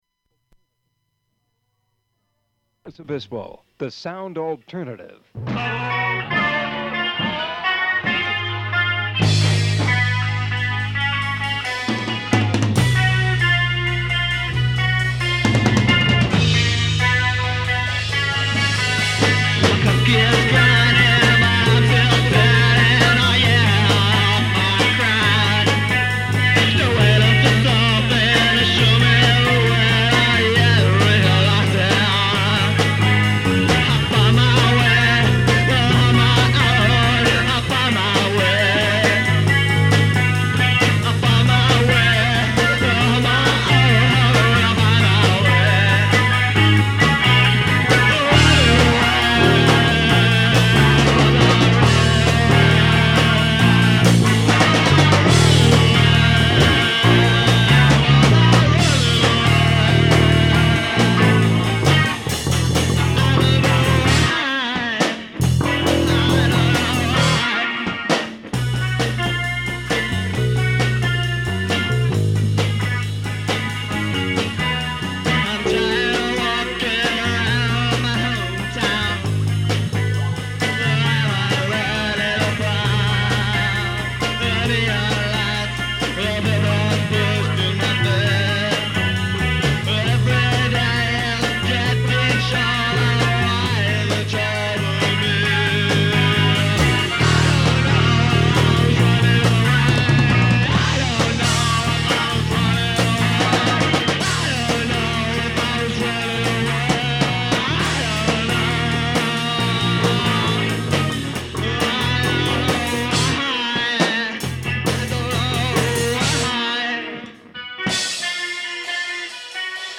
Improvised song begins after station tag
Band improvises briefly while a member tunes
drums
guitar
bass
vocals
Open reel audiotape